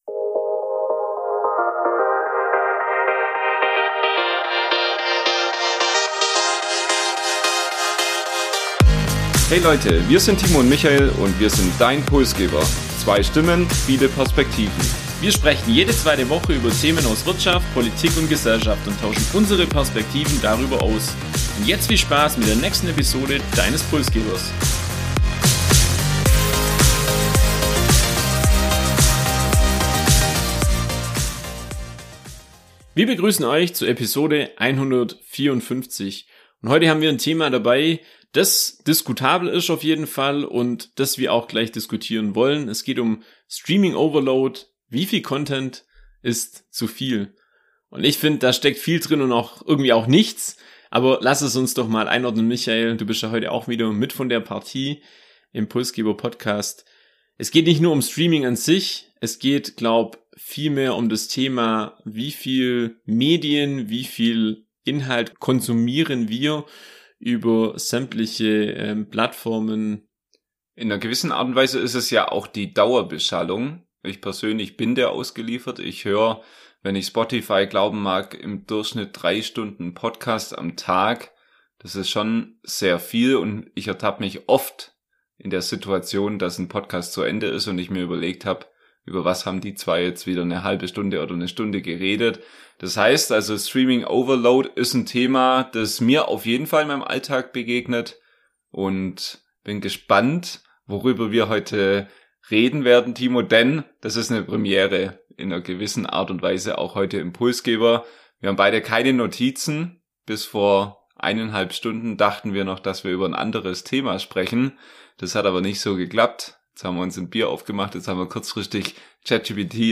#154 Streaming Overload - wie viel Content ist zu viel? ~ Pulsgeber - zwei Stimmen, viele Perspektiven Podcast